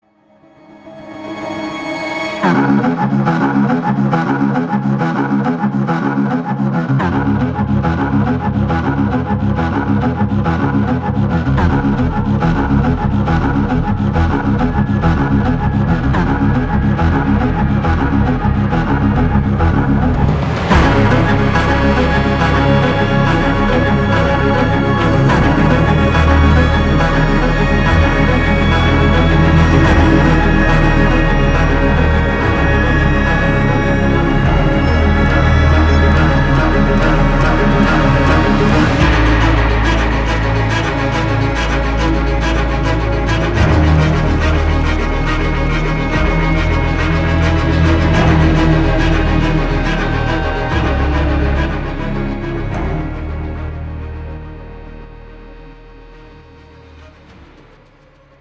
• Качество: 320, Stereo
саундтреки
без слов
инструментальные
тревожные
виолончель
оркестр
эпичные